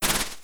Wings.wav